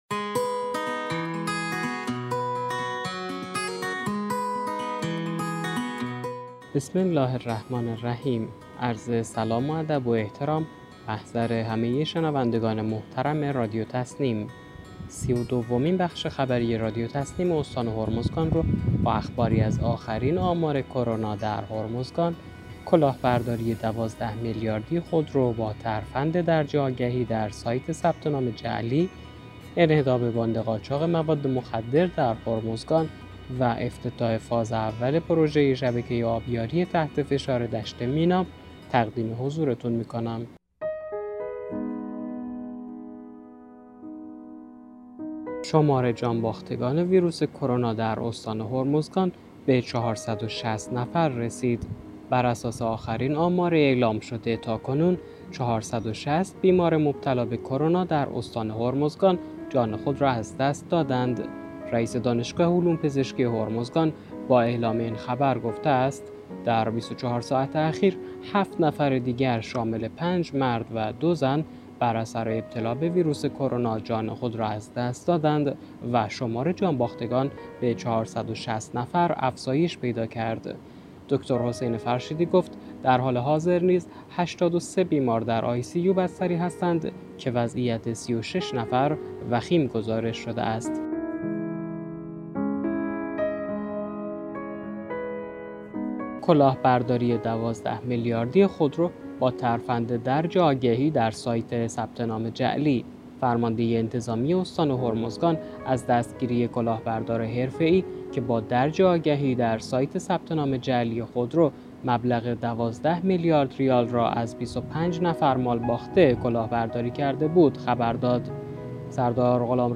به گزارش خبرگزاری تسنیم از بندرعباس، سی‌ و دومین بخش خبری رادیو تسنیم استان هرمزگان با اخباری از آخرین آمار کرونا در هرمزگان، کلاهبرداری 12 میلیاردی خودرو با ترفند درج آگهی در سایت ثبت نام جعلی، انهدام باند قاچاق مواد مخدر در هرمزگان و افتتاح فاز اول پروژه شبکه آبیاری تحت فشار دشت میناب منتشر شد.